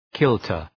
Προφορά
{‘kıltər}